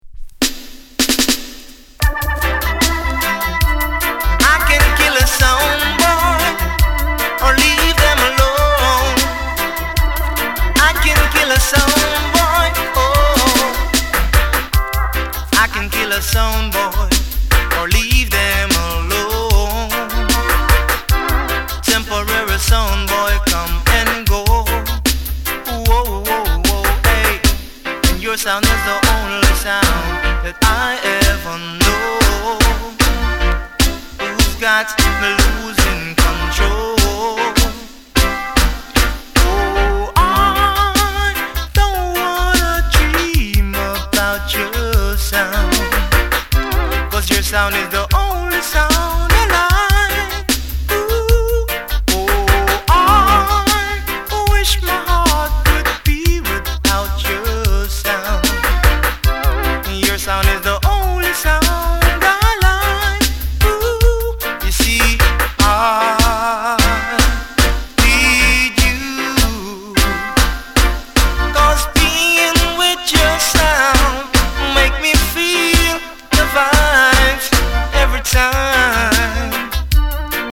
Genre: Reggae/Dancehall